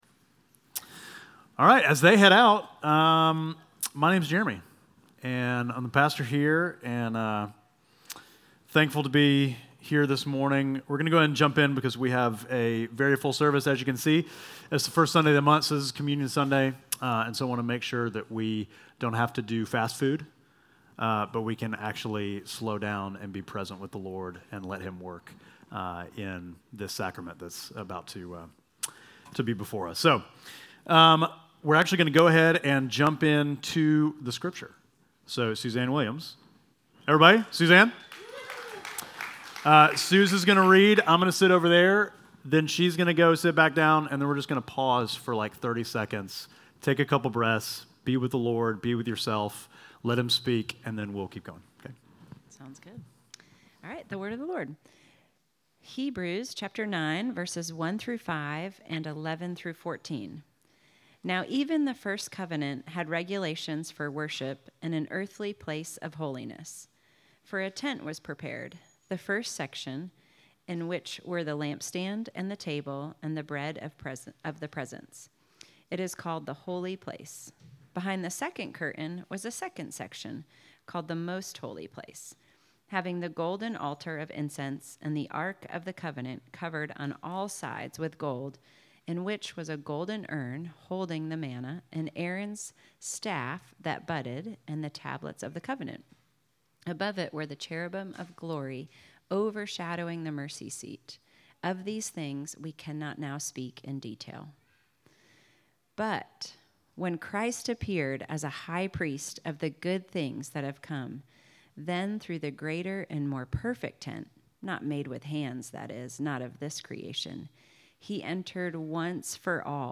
Midtown Fellowship Crieve Hall Sermons Jesus: The Sacrifice Nov 03 2025 | 00:44:03 1x 00:00 / 00:44:03 Subscribe Share Apple Podcasts Spotify Overcast RSS Feed Share Link Embed